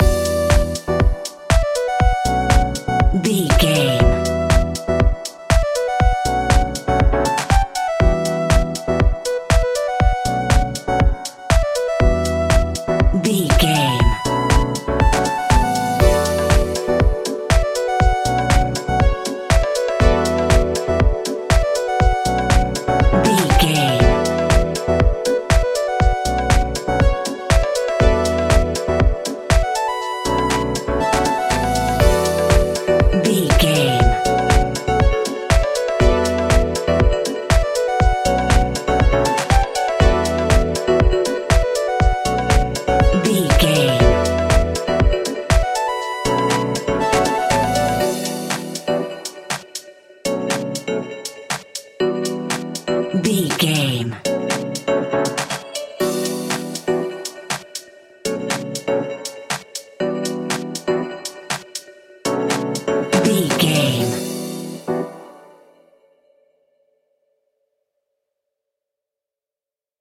royalty free music
Dorian
groovy
uplifting
energetic
drum machine
synthesiser
funky house
deep house
nu disco
upbeat
instrumentals
electric guitar
clavinet
synth bass
horns